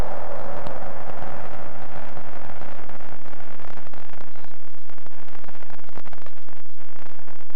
As the wax fell away, and the rod became more and more saturated, the level predictably came up and in the end, all that was left was noise.
If you want to hear the first recording, raw as it came off the sensor (warning: not DC centered!) click